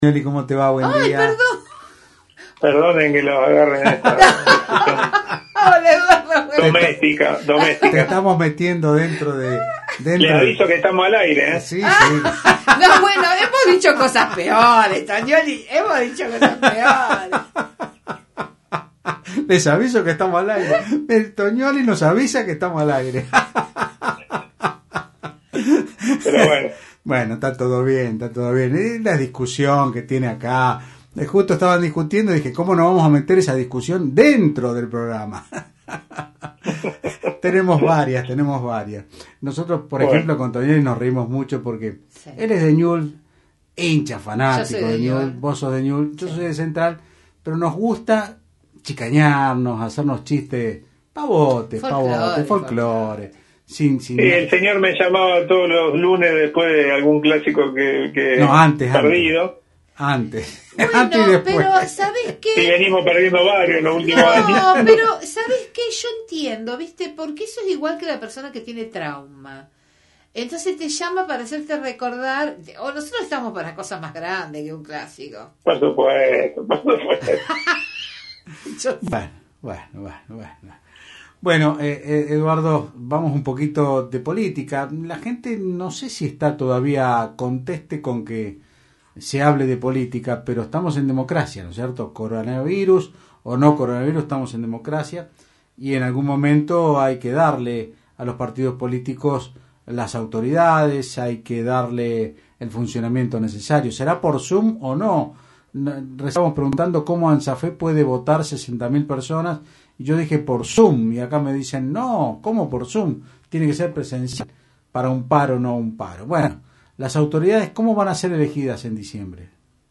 El Titular del PJ Eduardo Toniolli dijo en Otros Ámbitos (Del Plata Rosario 93.5) que el consejo ejecutivo del Partido Justicialista (PJ) provincial se reunió de manera virtual para delinear su agenda de los próximos meses y trazó un balance sobre lo decidido en el encuentro, en el que, además de ratificar el apoyo a la gestión de Omar Perotti, se comenzó a delinear el cronograma electoral para renovar las autoridades partidarias antes de fin de año.